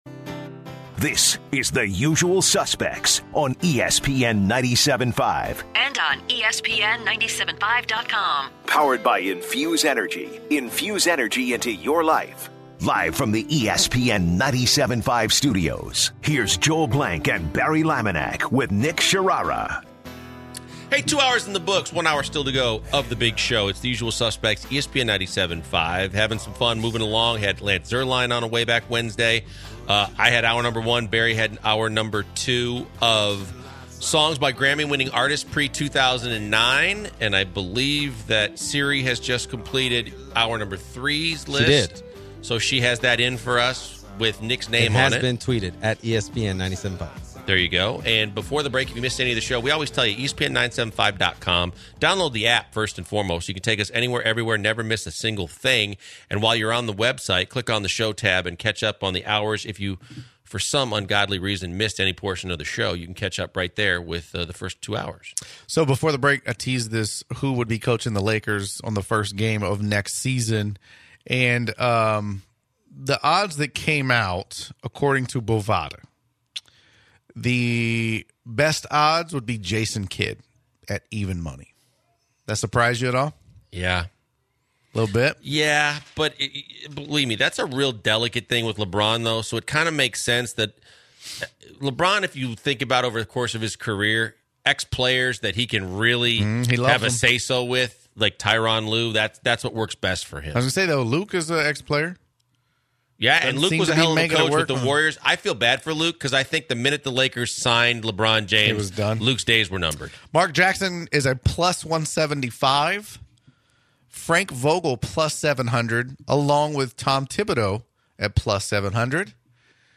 The guys get the final hour going with a riveting conversation about potential Lakers Head coaching replacements should the team part ways with Luke Walton. Then, they take some calls including one about Charles Barkley and generosity.